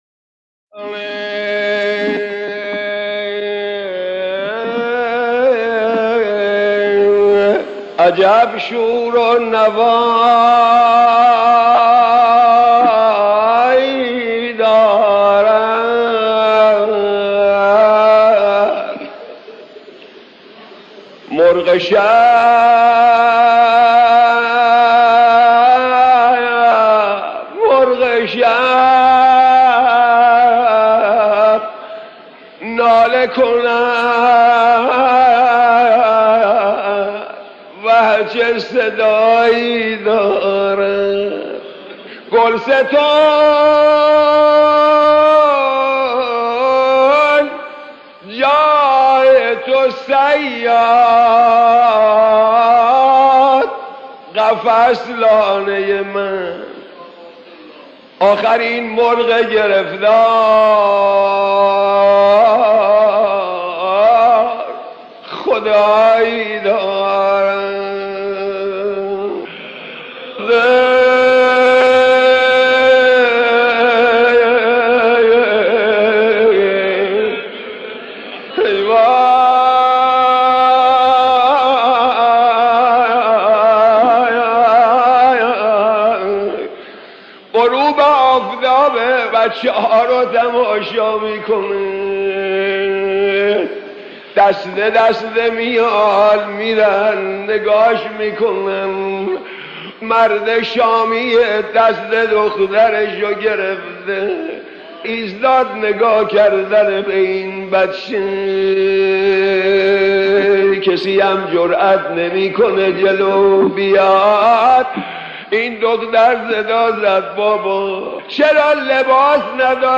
صوت | نغمه‌های عاشورایی از مداحان قدیم تهران
غزل مصیبت در وصف تنهایی حضرت رقیه (س)